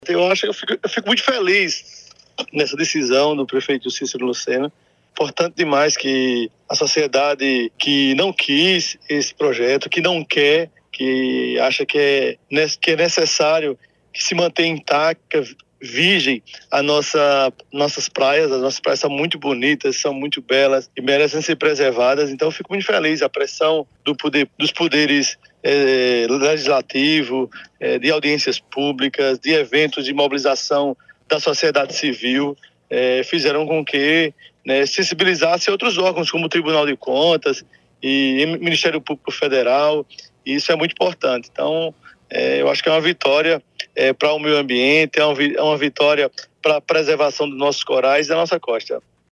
Os comentários de Chió foram registrados pelo programa Correio Debate, da 98 FM, de João Pessoa, nesta terça-feirea (11/07).